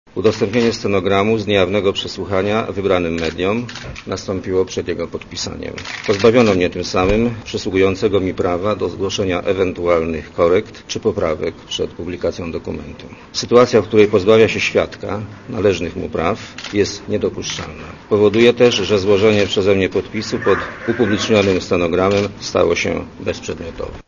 Nie podpiszę stenogramu z mojego przesłuchania podczas tajnego posiedzenia komisji śledczej ds. PKN Orlen, ponieważ fragmenty stenogramu zostały udostępnione mediom - powiedział dziennikarzom były premier Leszek Miller.
Mówi Leszek Miller